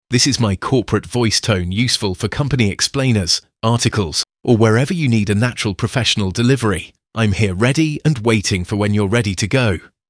Male
English (British), English (Neutral - Mid Trans Atlantic)
Adult (30-50), Older Sound (50+)
Corporate